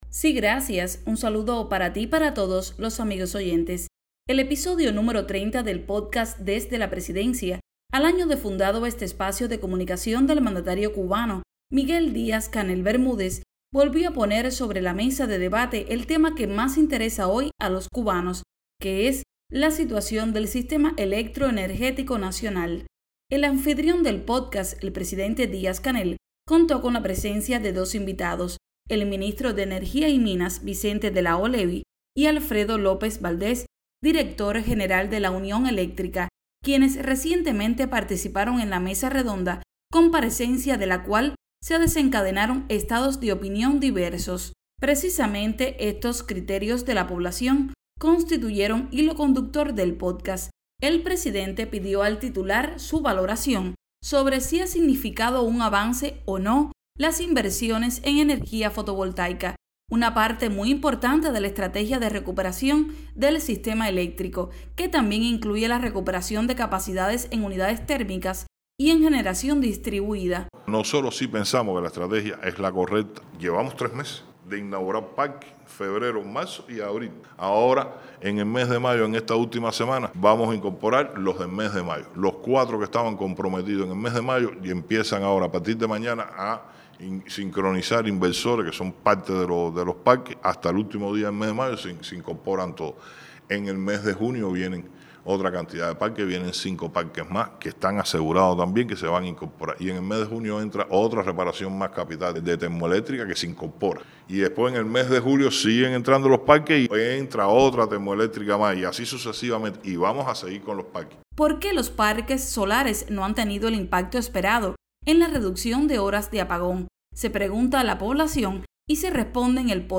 En el más reciente pódcast (el 31) Desde la Presidencia, el Primer Secretario del Comité Central del Partido Comunista de Cuba, Miguel Díaz-Canel Bermúdez, abordó la actual situación nacional, referida a un tema tan sensible y urgente como el de la generación eléctrica. Para las posibles soluciones, hay estrategias; sobre eso conversó el mandatario, acompañado del ministro de Energía y Minas, Vicente de la O Levy, así como de Alfredo López Valdés, director general de la Unión Eléctrica.